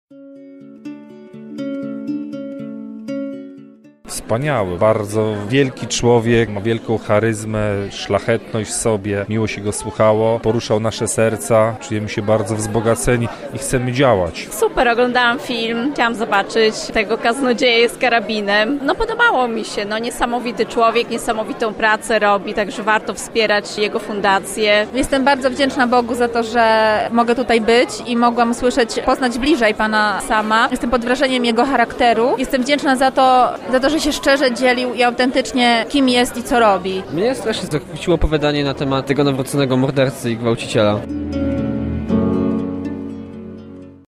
O wrażenia po spotkaniu z „Kaznodzieją z Karabinem” widzów spytał nasz reporter